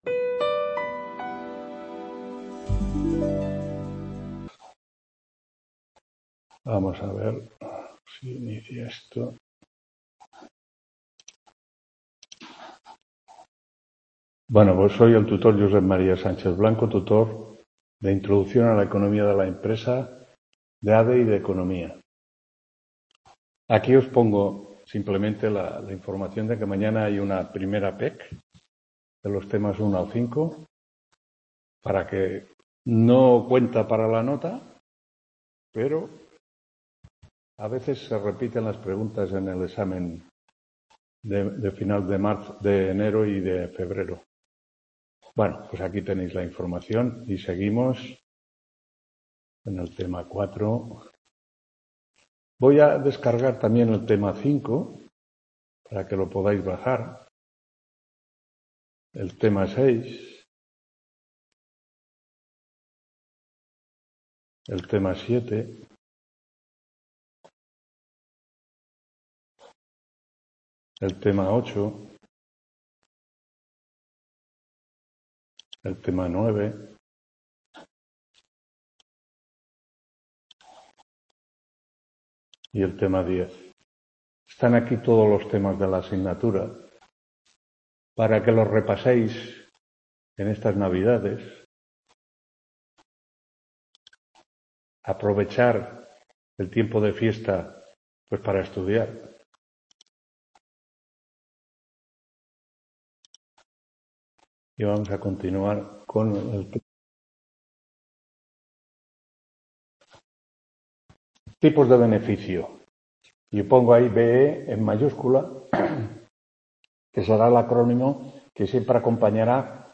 8ª TUTORÍA INTRODUCCIÓN A LA ECONOMÍA DE LA EMPRESA 12…